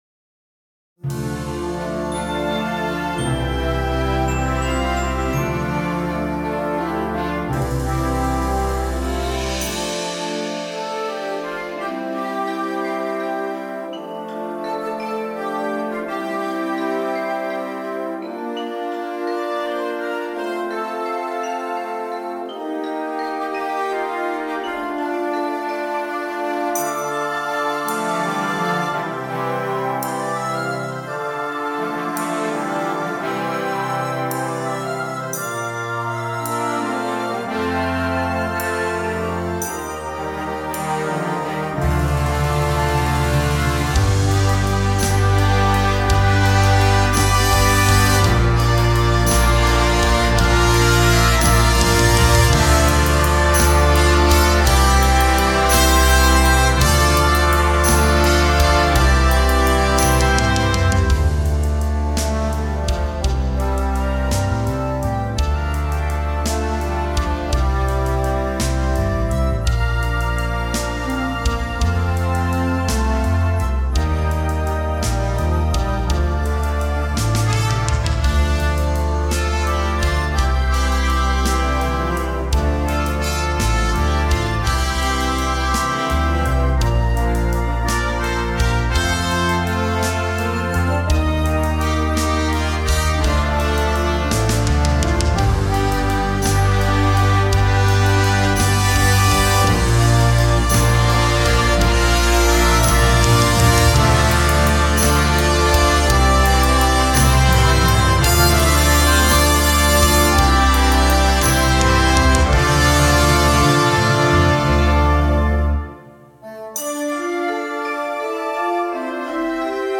Concert Band
Flutes 1-2
Oboes 1-2
Bb Clarinets 1-2-3
Eb Alto Saxophones 1-2
Horns in F 1-2
Bb Trumpets 1-2-3
Tenor Trombones 1-2
Euphonium
Tuba
Timpani
Drum Set
Glockenspiel
Vibraphone
Bass Guitar